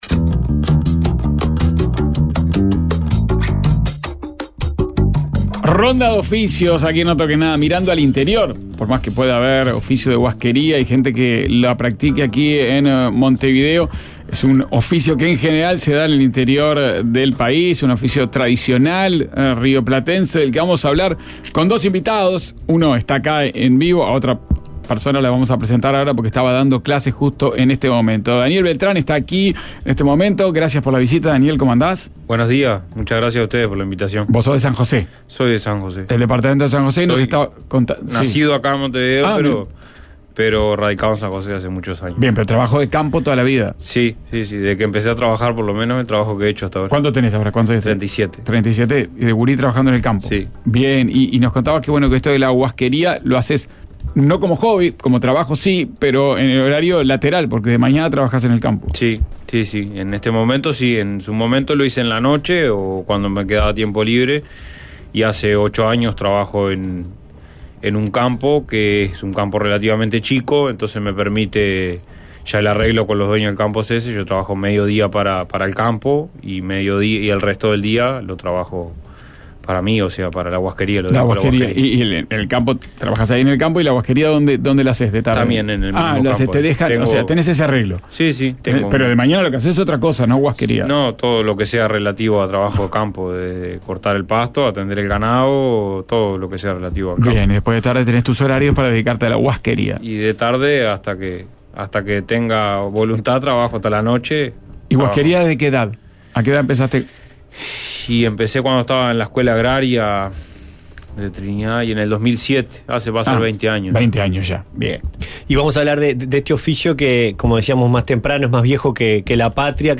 Hablamos con dos guasqueros en el ciclo de oficios.